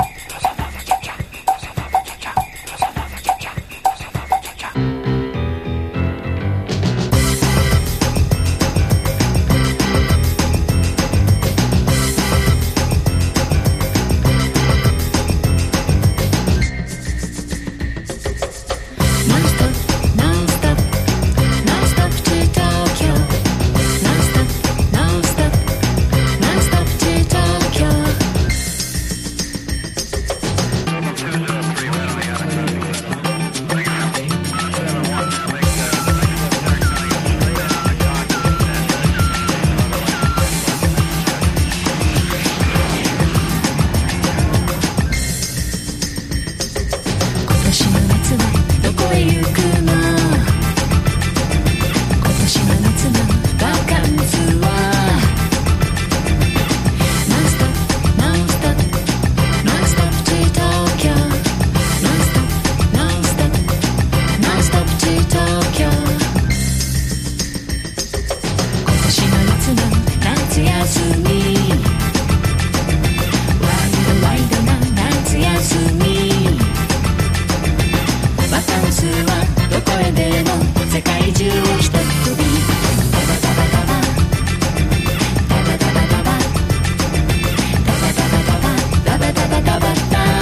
言わずもがな、とことんキャッチーで、とことんハッピーな1999年発表の人気曲。